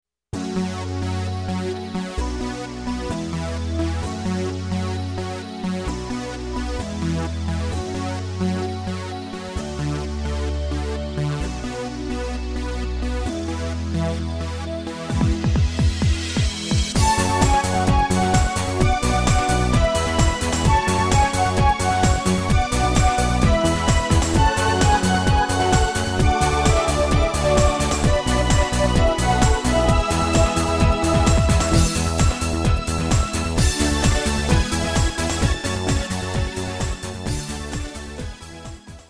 Žánr: Dance